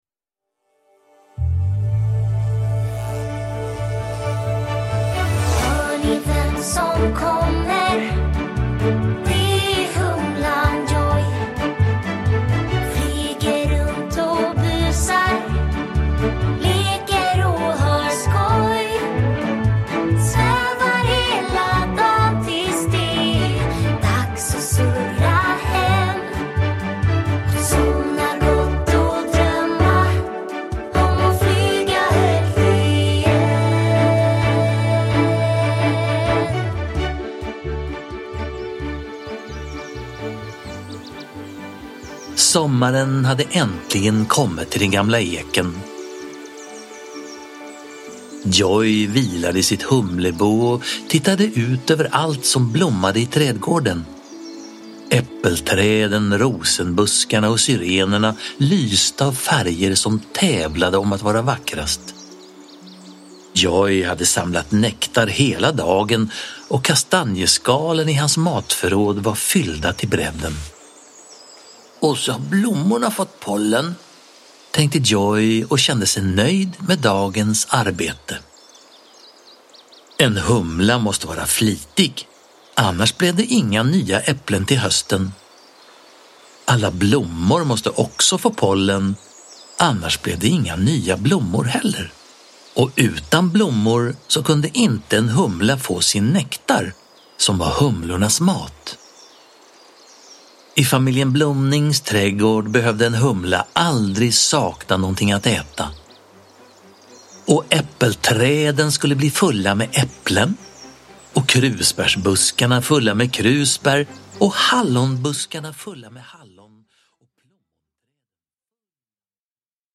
Djojj hjälper ett bi – Ljudbok – Laddas ner
Uppläsare: Staffan Götestam